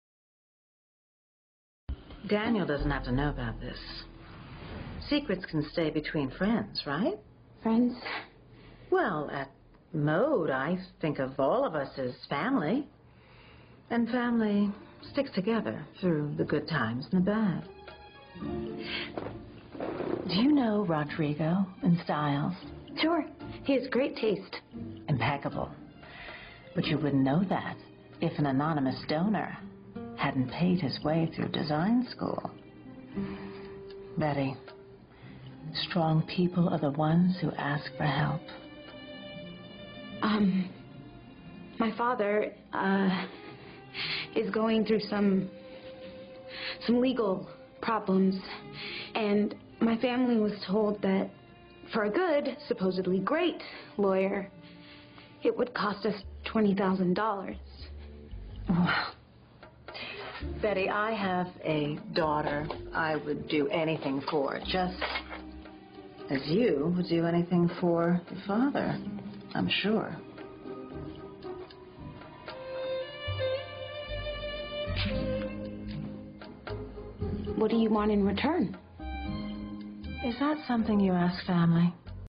在线英语听力室影视剧中的职场美语 第75期:同甘共苦的听力文件下载,《影视中的职场美语》收录了工作沟通，办公室生活，商务贸易等方面的情景对话。